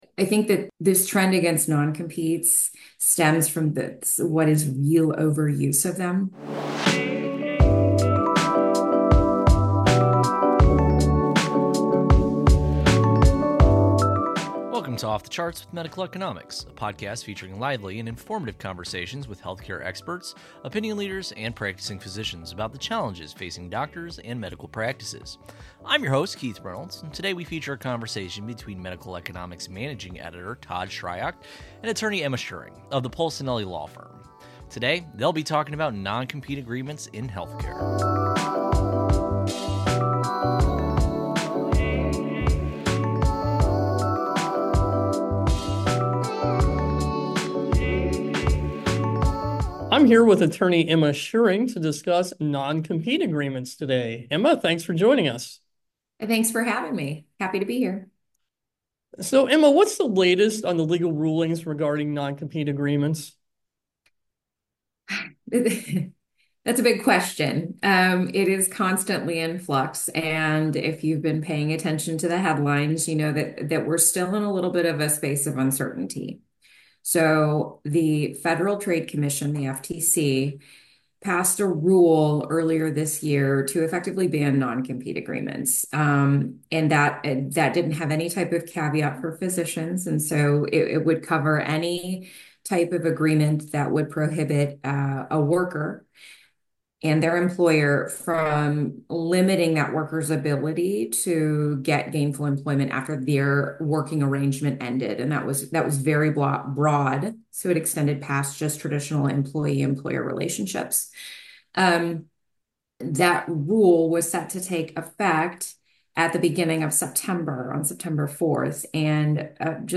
Music Credits: Lofi Chillout Hip Hop Beat